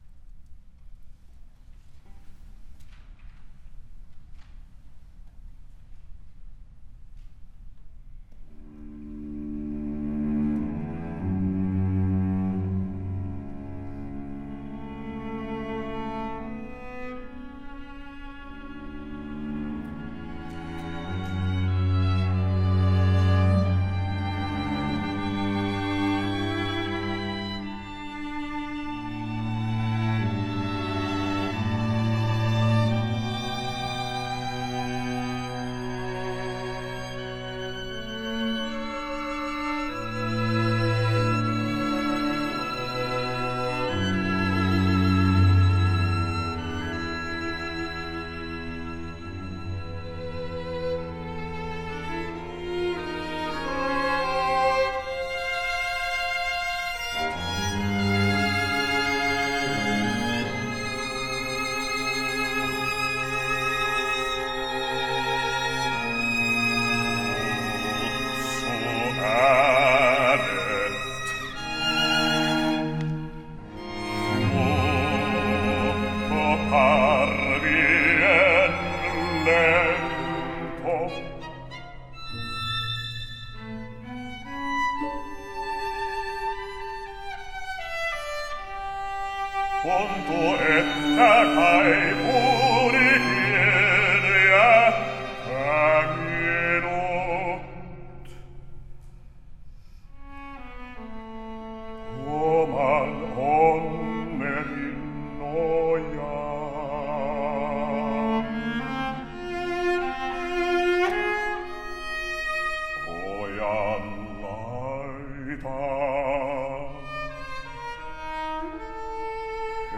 Song cycle for bariton and string quartet (version A)